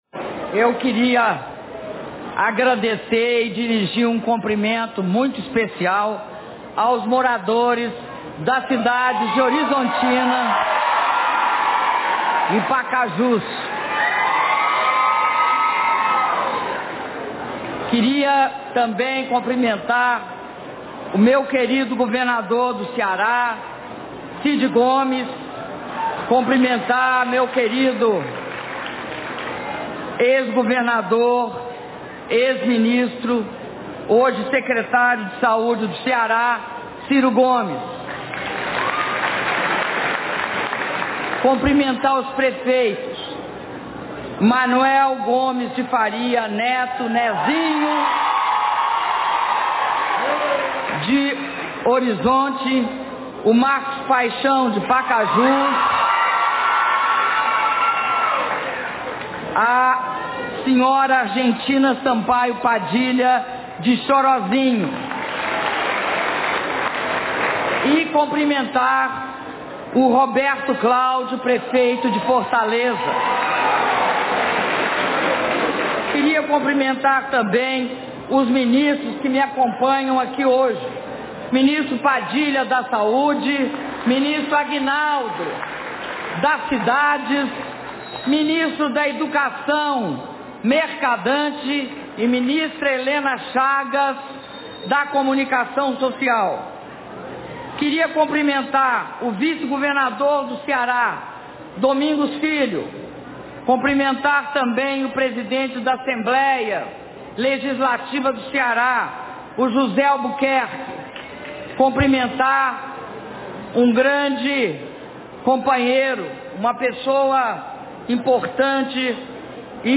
Áudio do discurso da Presidenta da República, Dilma Rousseff, na cerimônia de inauguração da Unidade de Pronto Atendimento - UPA 24h de Horizonte/Pacajus - Horizonte/CE